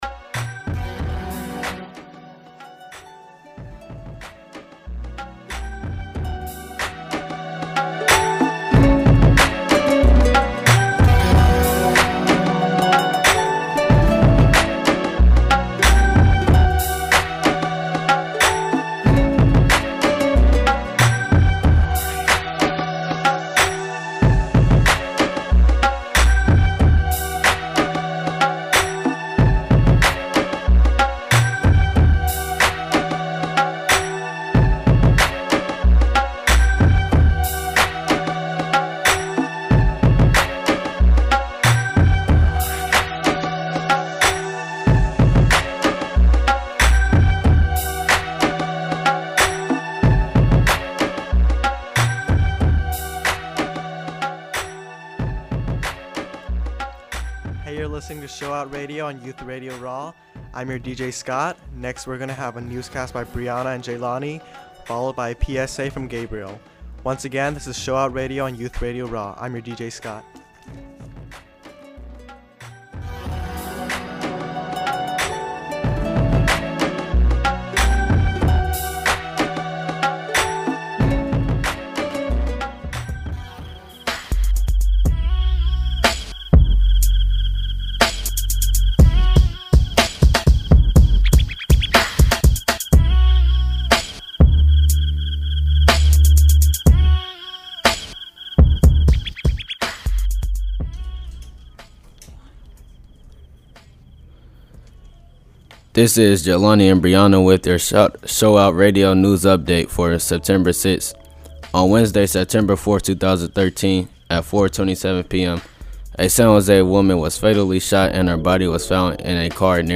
On today's show we have for you three DJ's playing some good music as always, a PSA on birth control knowledge, three good commentaries revolving around music,